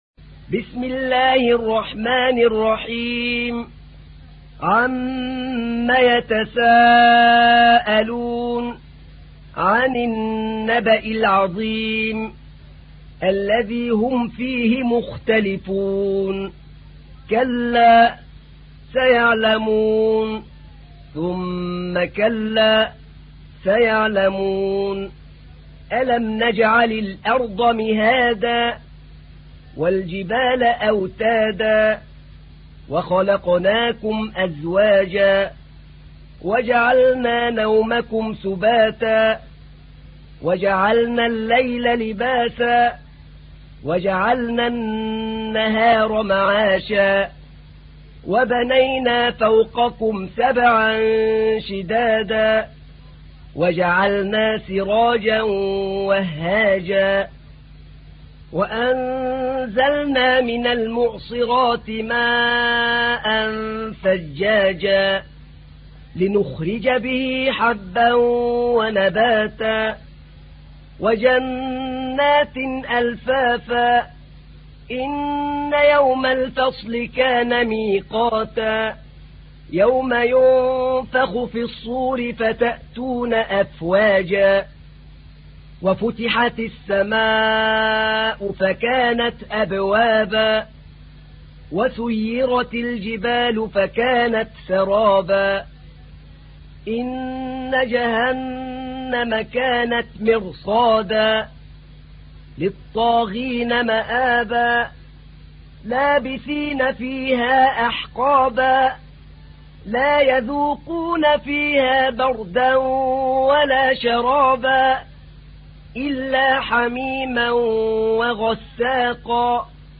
تحميل : 78. سورة النبأ / القارئ أحمد نعينع / القرآن الكريم / موقع يا حسين